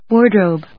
音節ward・robe 発音記号・読み方
/wˈɔɚdròʊb(米国英語), wˈɔːdr`əʊb(英国英語)/